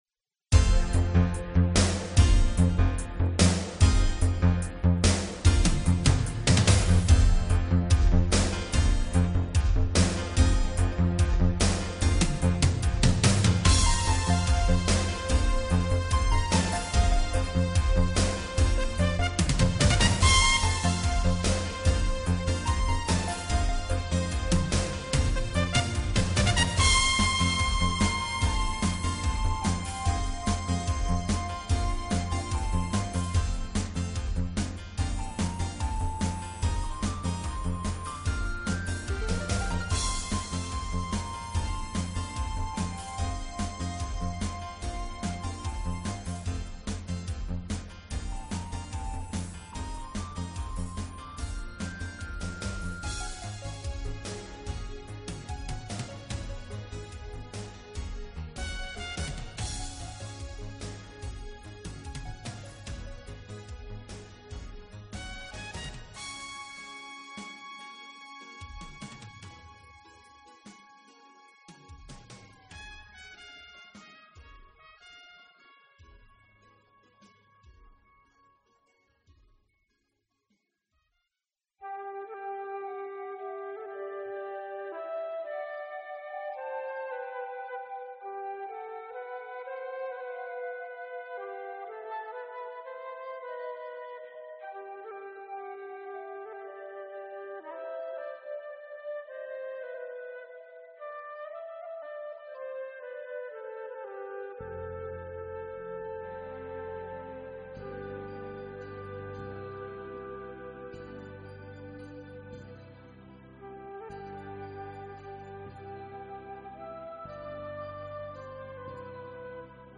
Fun and upbeat!